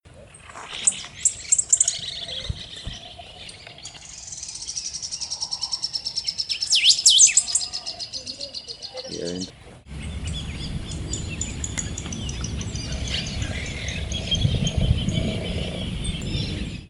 Short-billed Canastero (Asthenes baeri)
Country: Argentina
Province / Department: Entre Ríos
Condition: Wild
Certainty: Recorded vocal